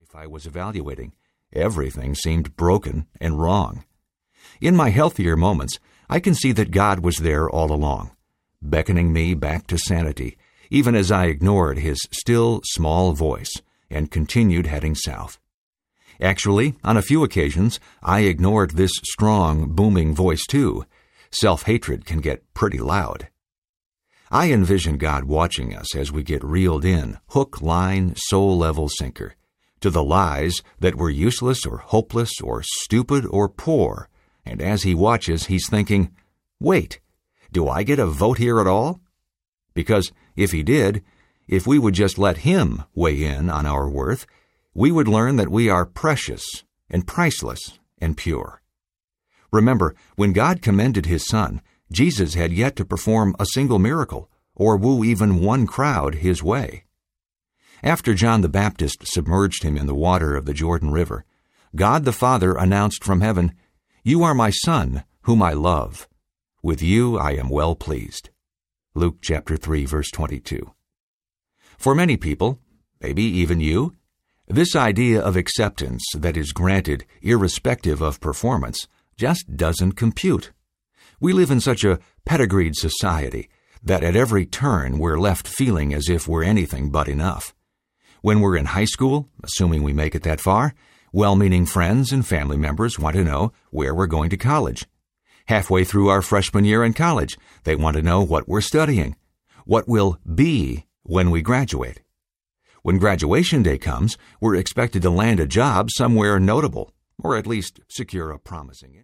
Speak Life Audiobook
Narrator
7.17 Hrs. – Unabridged